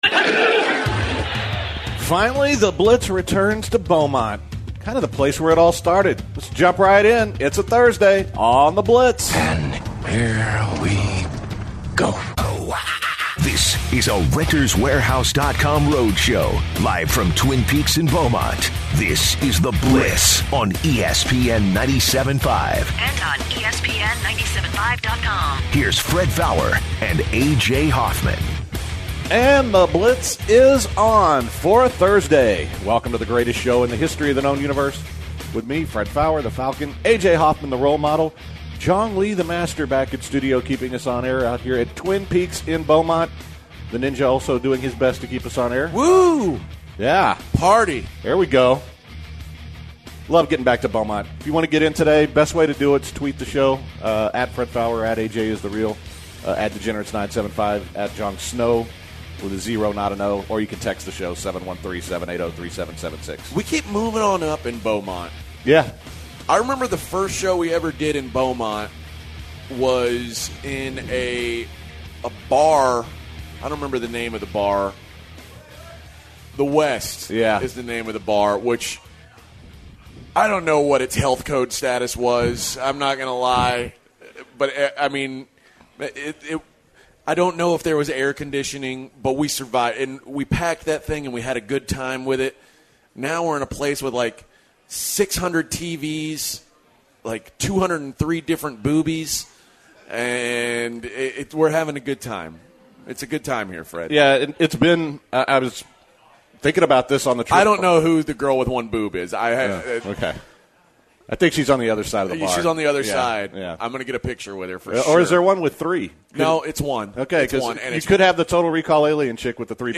are at Twin Peaks in Beaumont but it's a sober and short show. The guys talked about LaVarr Ball's interview with Steven A. Smith. They also discussed the difference in peak between genders.